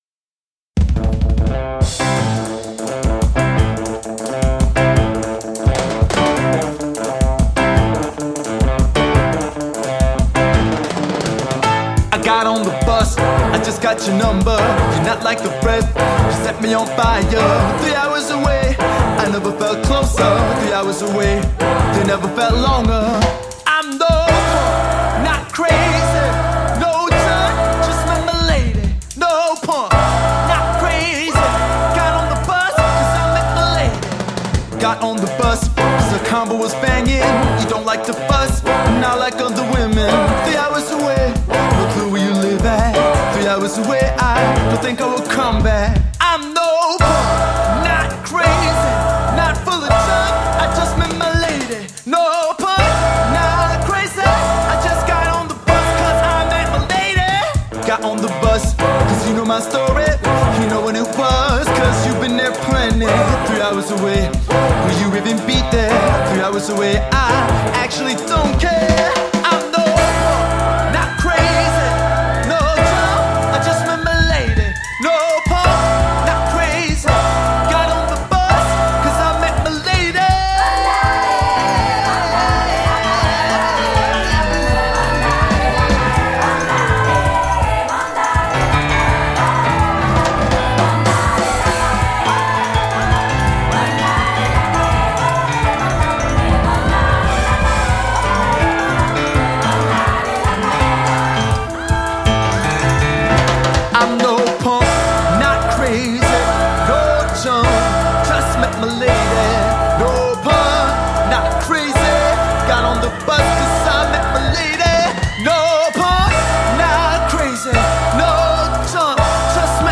guitar
piano
drums